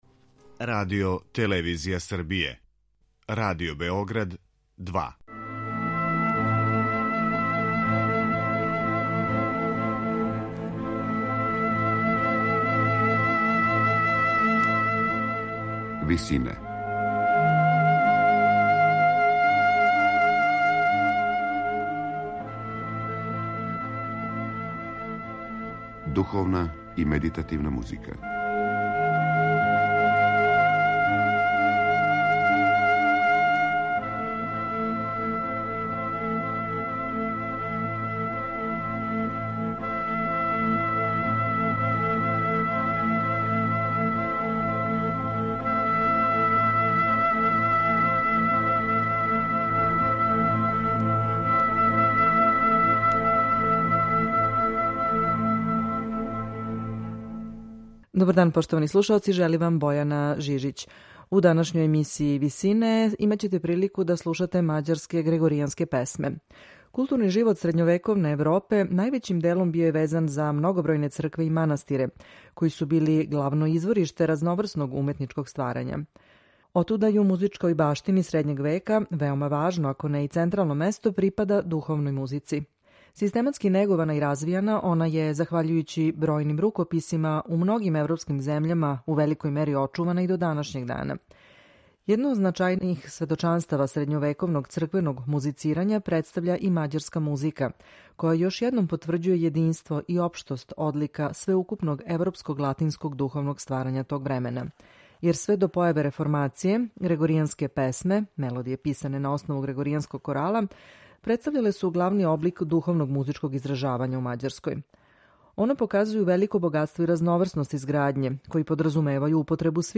Значајан допринос средњовековној музичкој баштини дала је Мађарска, чије грегоријанске песме представљају драгоцено сведочанство о разноврсности црквеног музицирања у Европи тог доба.
Мађарске грегоријанске песме
Синтеза универзалних одлика латинског духовног певања средњег века и елемената мађарског фолклора обезбедила је овим напевима посебно место у историји музике. Мађарске грегоријанске песме ће у данашњој емисији Висине изводити ансамбл „Schola Hungarica", којим диригује Ласло Добзај.